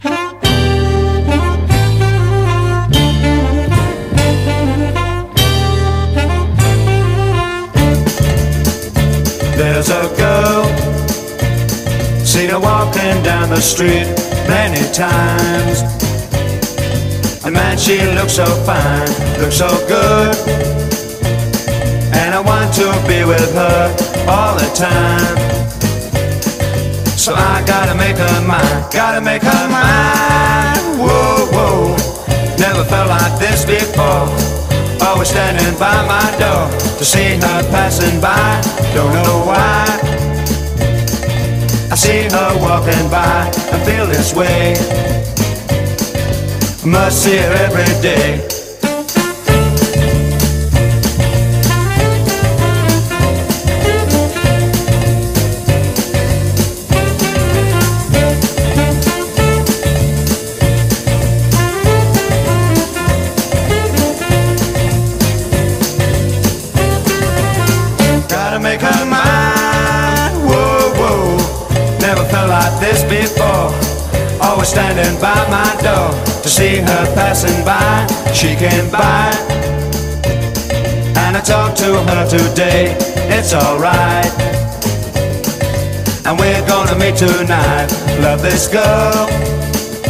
80'S/NEW WAVE. / POST PUNK. / NO WAVE / JAPANESE POST PUNK
衝動と脱力のはざまを突くNO WAVEの爆走EP！
突き抜けるサックスとパンキッシュなガレージ・サウンドが火花を散らす